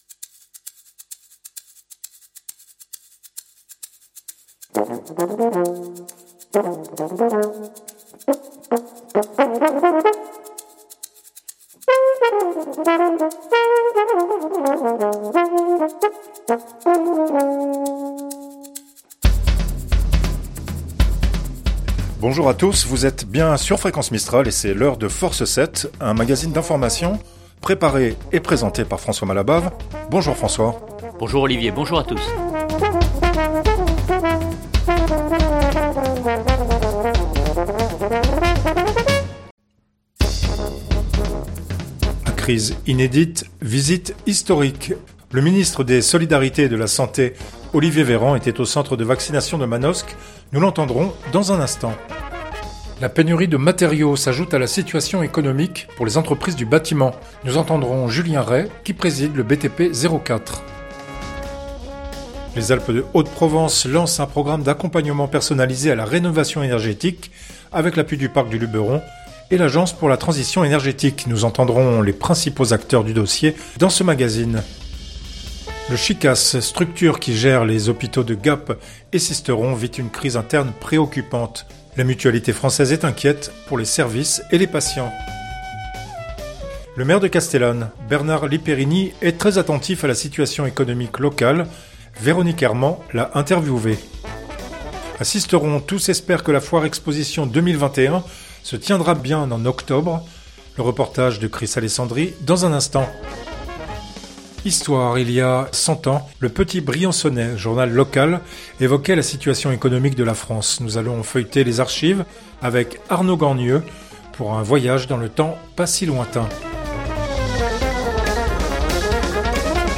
un magazine d’information